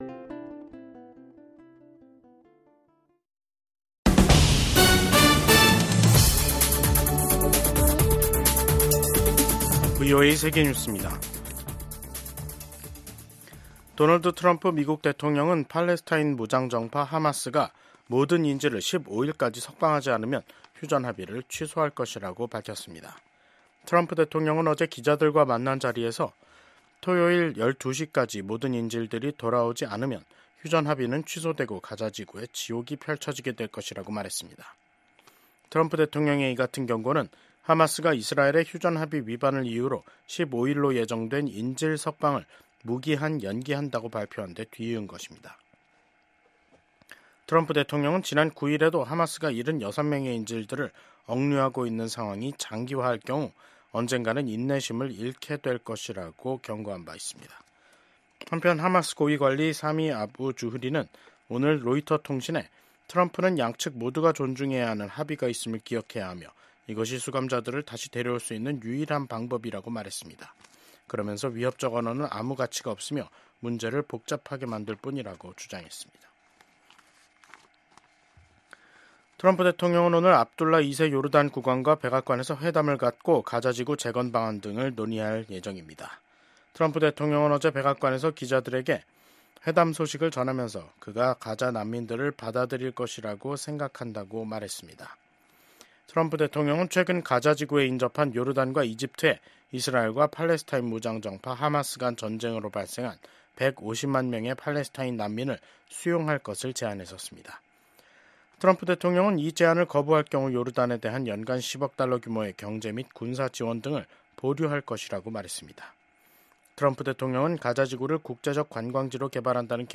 VOA 한국어 간판 뉴스 프로그램 '뉴스 투데이', 2025년 2월 11일 2부 방송입니다. 도널드 트럼프 미국 행정부 출범 이후 대미 담화 빈도를 크게 늘린 북한이 이번엔 미국의 원자력 추진 잠수함(SSN)의 부산 입항을 비난하는 담화를 냈습니다. 미국의 한반도 전문가들은 지난주 미일 정상회담이 북한 비핵화와 미한일 3국 공조를 강조한 것에 주목하며 한반도와 역내 평화와 안보에 기여할 것으로 기대했습니다.